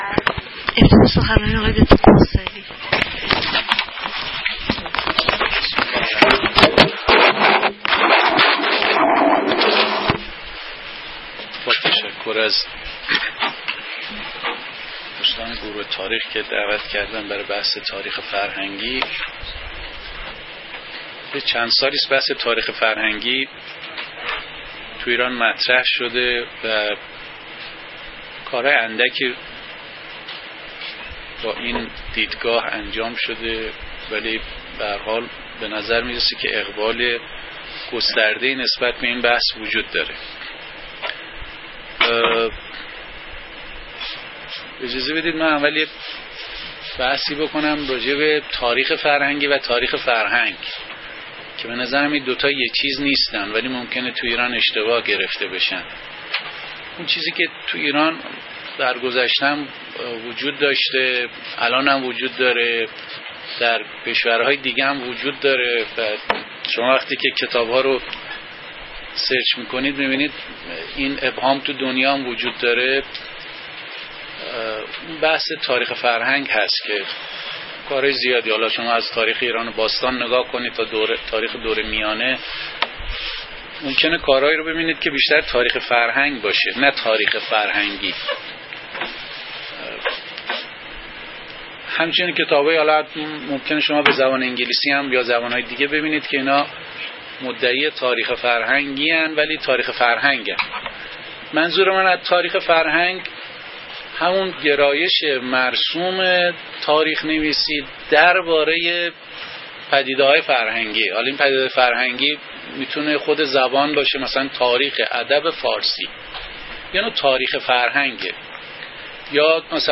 سخنرانی
در پژوهشگاه علوم انسانی و مطالعات فرهنگی برگزار شد.